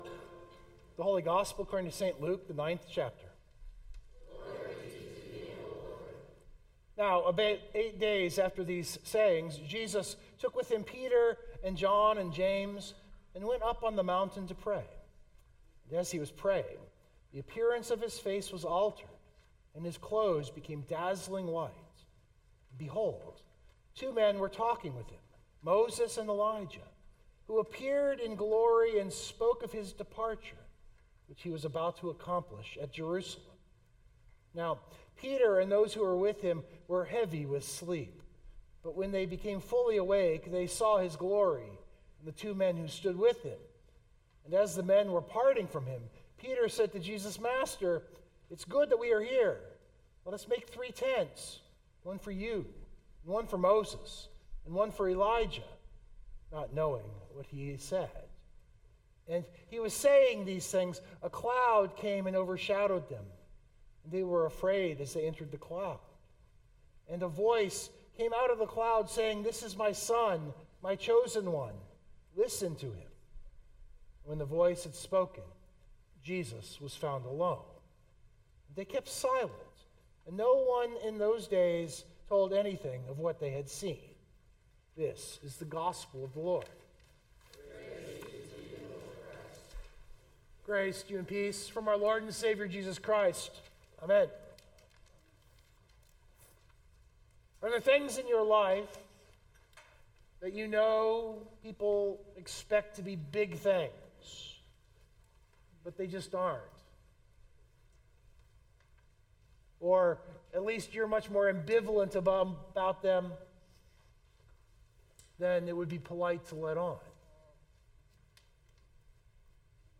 030225 Sermon Download Biblical Text: : Luke 9:28-36 The text is the Transfiguration.